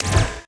SCIMisc_Sci Fi Shotgun Reload_02_SFRMS_SCIWPNS.wav